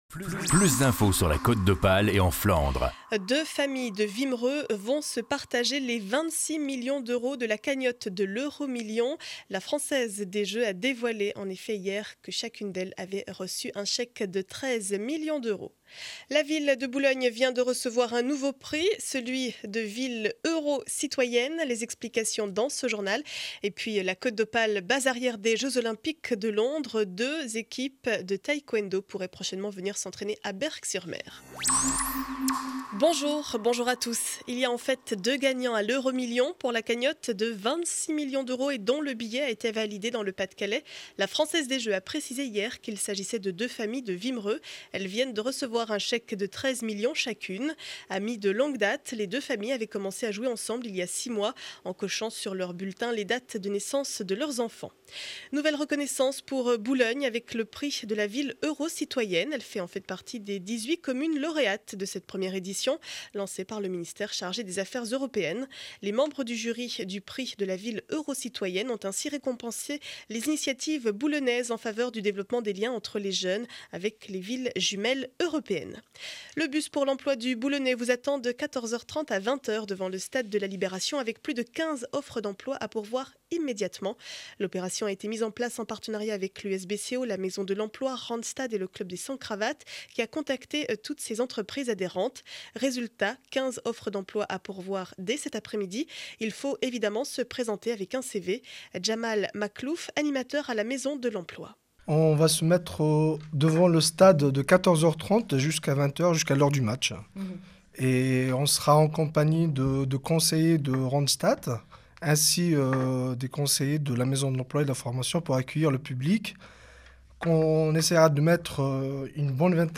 Journal du vendredi 06 avril 2012 7 heures 30 édition du Boulonnais.